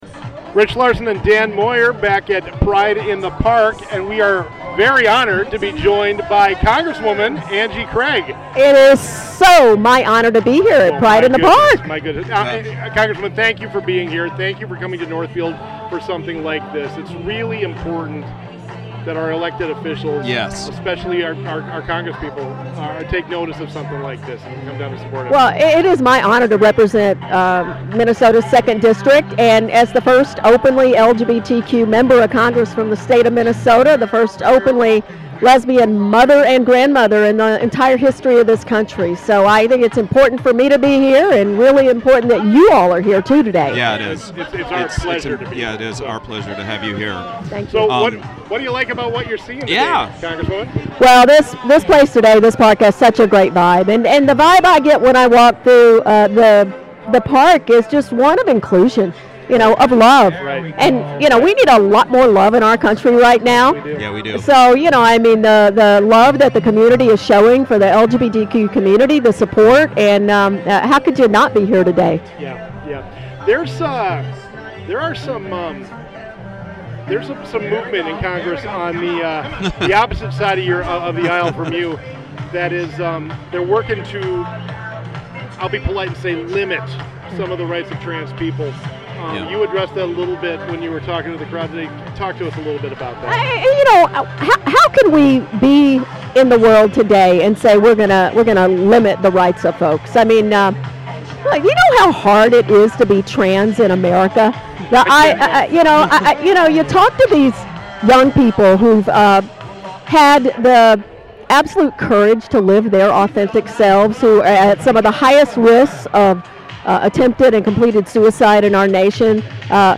Congresswoman Angie Craig discusses issues of the LGBTQ+ Community and gun control from Northfield’s Pride in the Park celebration.